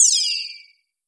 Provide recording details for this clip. -reduced SFX Quality.